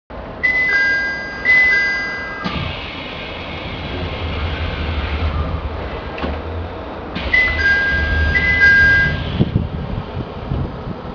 〜車両の音〜
HK100形ドアチャイム
閉→開。気動車によくあるドアチャイムを試用しています。